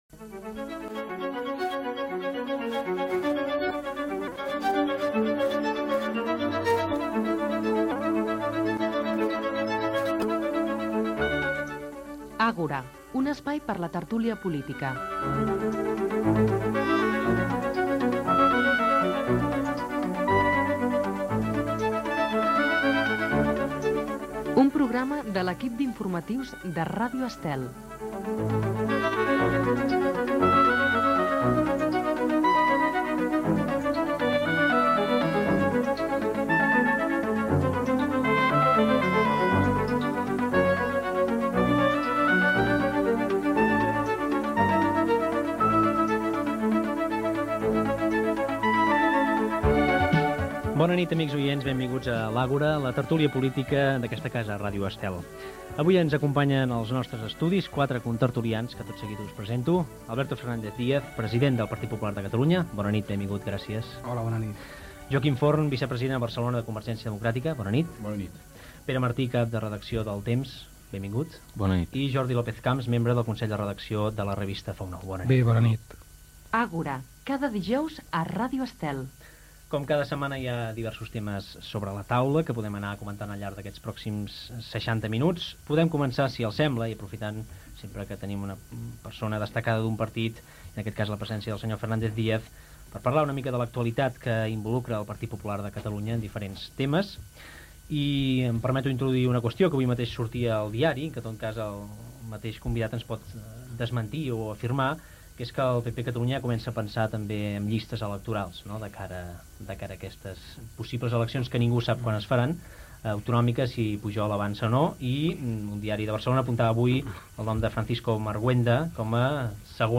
Careta del programa, presentació dels invitatsi i tertúlia pollítica
Gènere radiofònic Informatiu